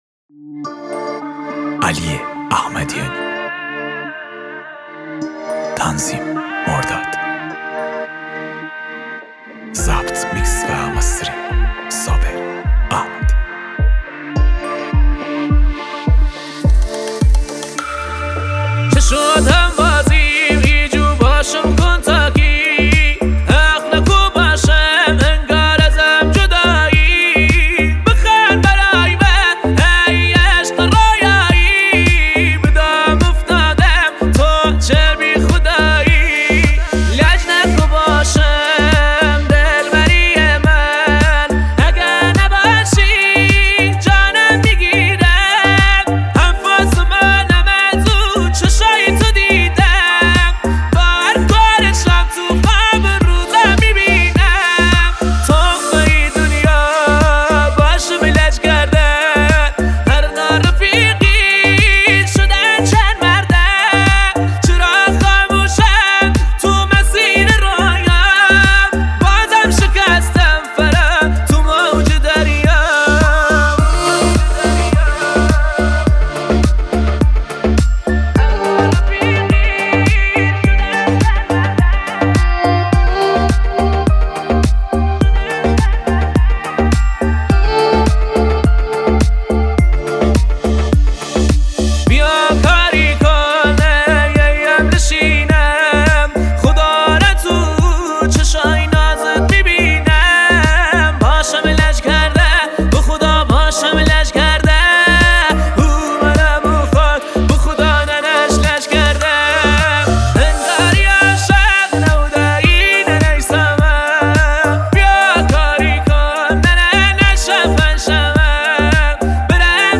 موزیک غمگین
موزیک کردی